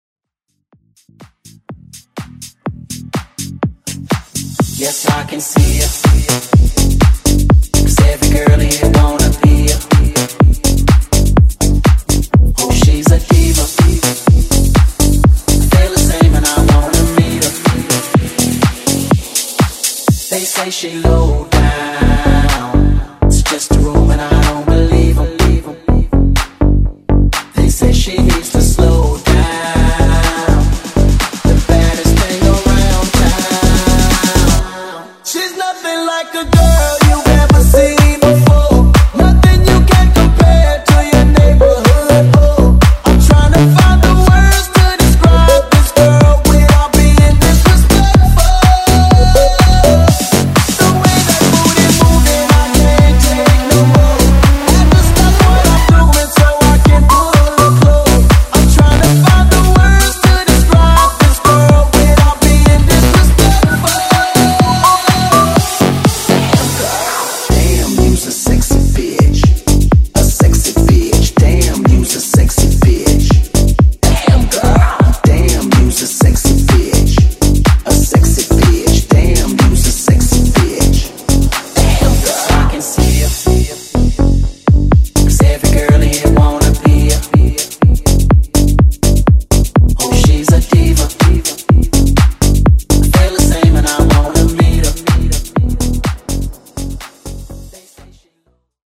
Genre: 50's
Clean BPM: 156 Time